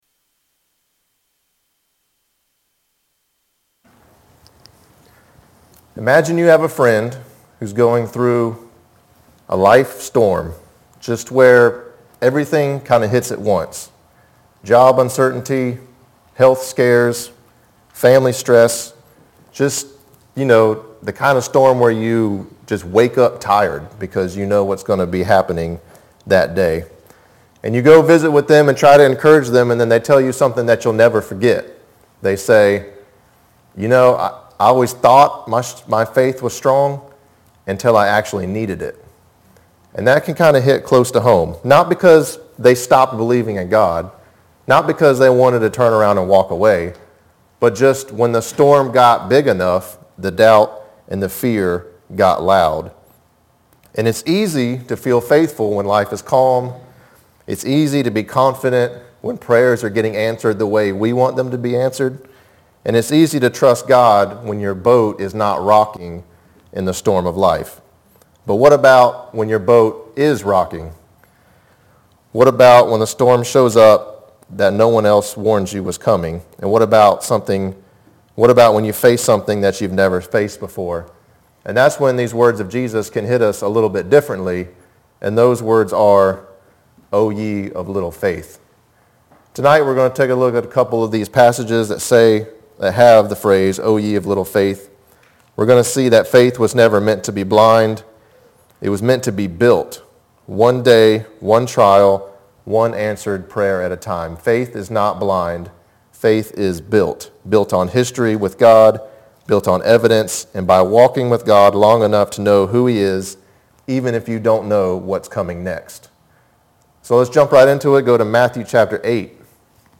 Sermon – Building Our Faith